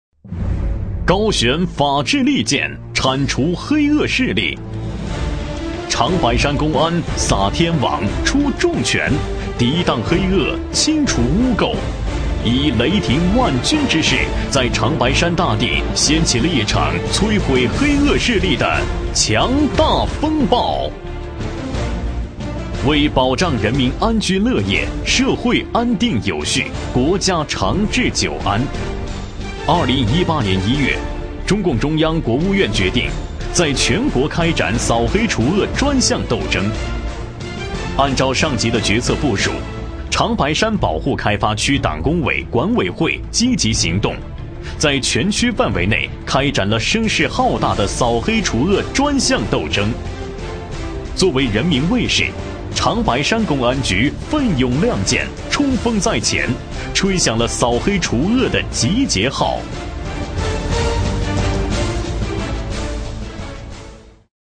专题男配
【男19号专题】严肃